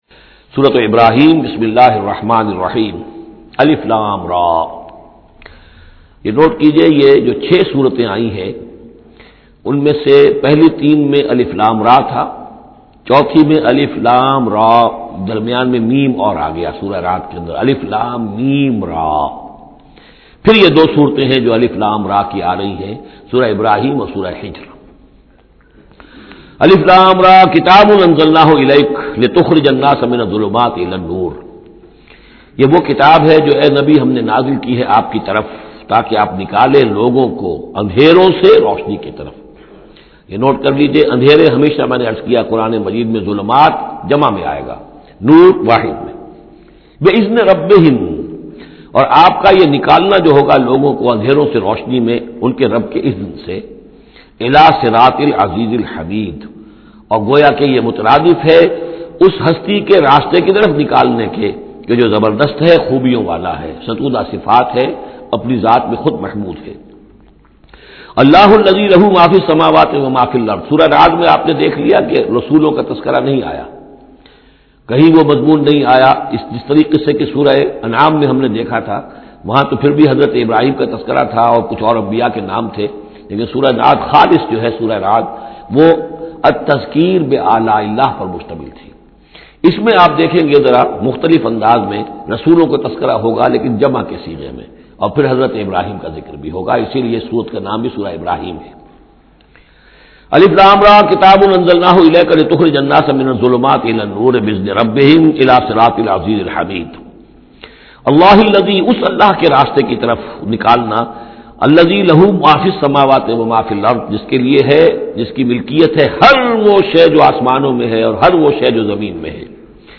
Surah Ibrahim Tafseer by Dr Israr Ahmed
Surah Ibrahim is 14th Chapter of Holy Quran. Listen online and download mp3 tafseer of Surah Ibrahim in the voice of Dr Israr Ahmed in urdu.